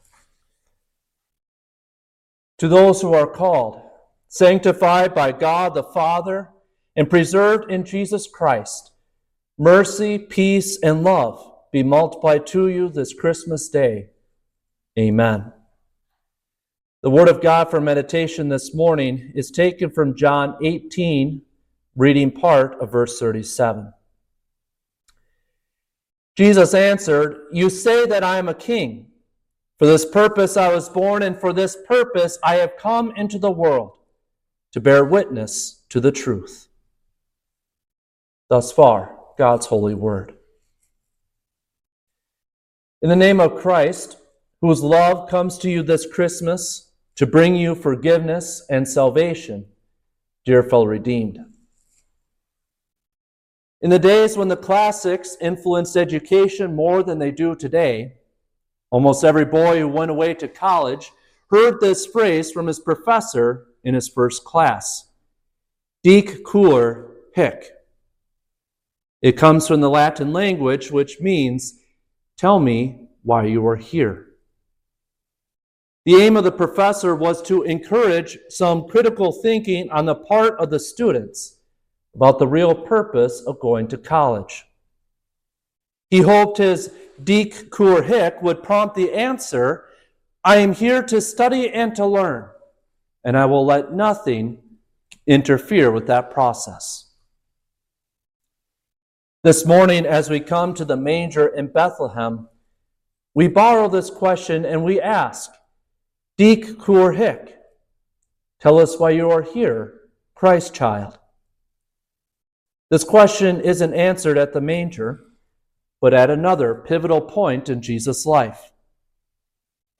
Christmas-Day-Sermon.mp3